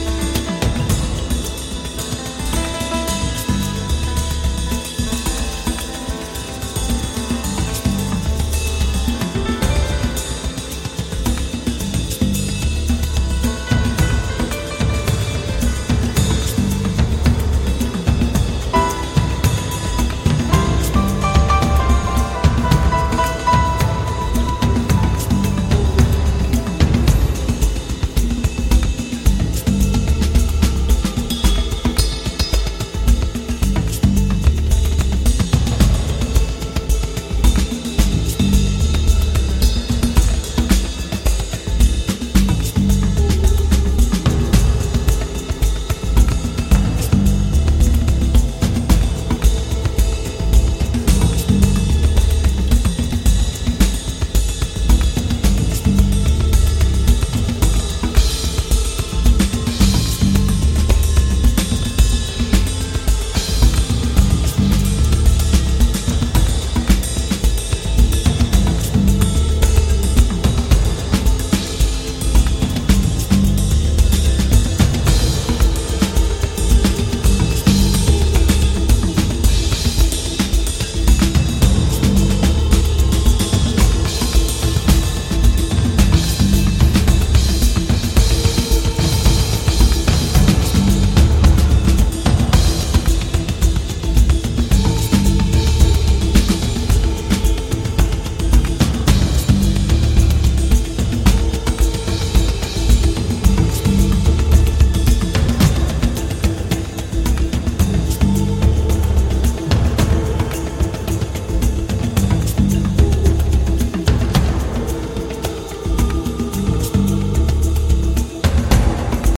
African Drums and Percussion